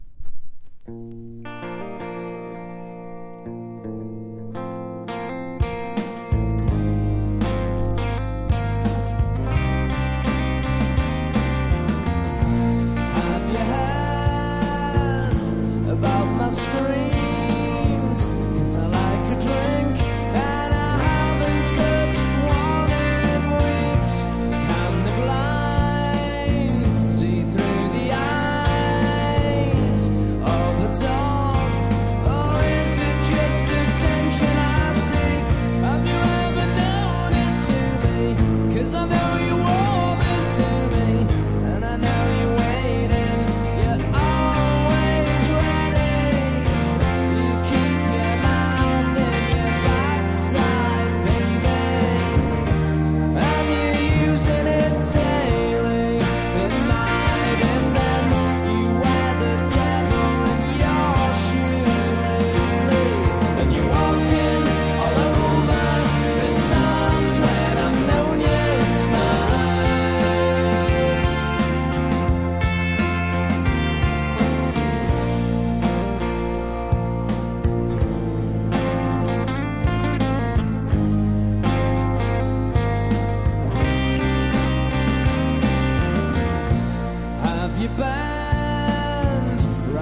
NEO ACOUSTIC / GUITAR POP (90-20’s)